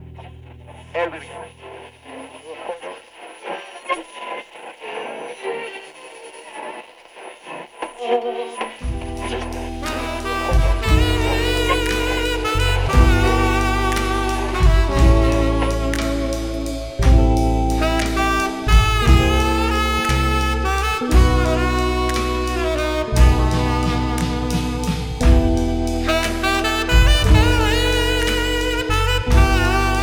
Hammond Organ
Saxophone